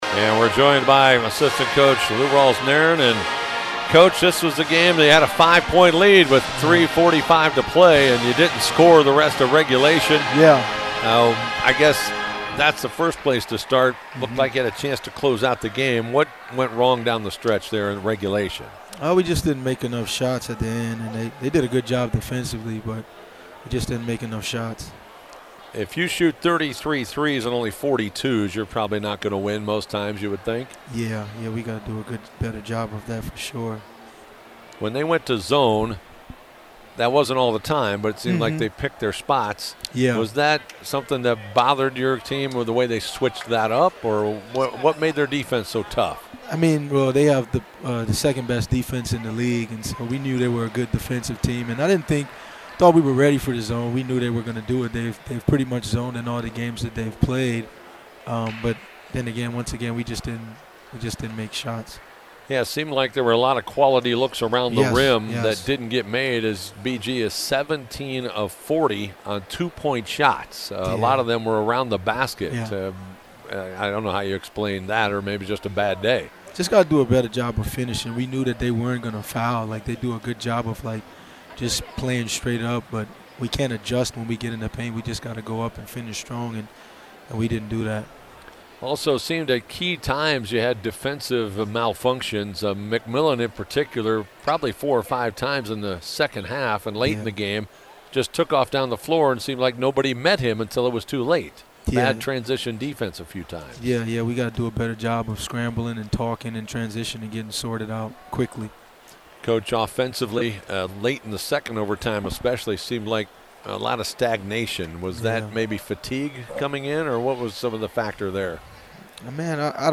Postgame Interview